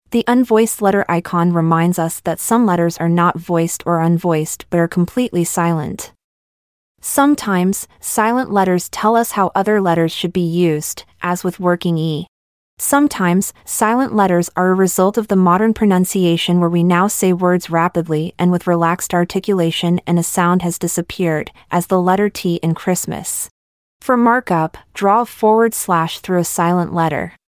unvoiced-letter-icon-lesson-AI.mp3